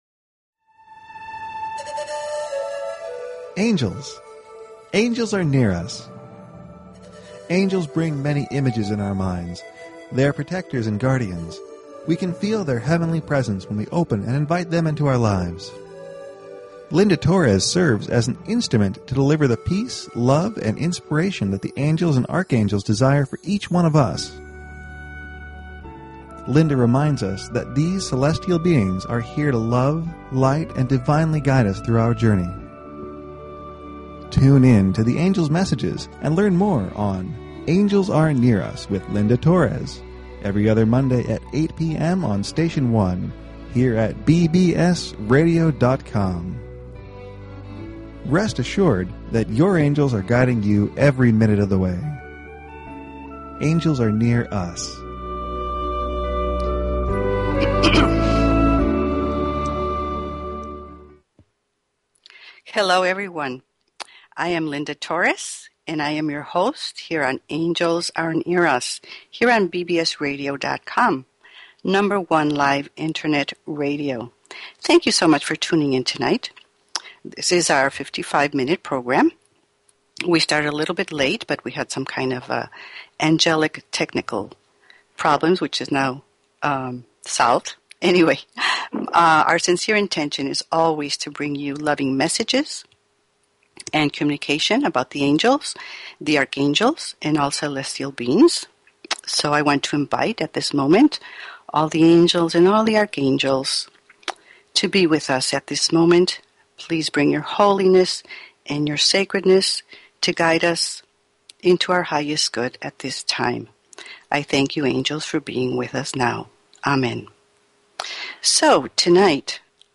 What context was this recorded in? The last 30 minutes of the show the phone lines will be open for questions and Angel readings.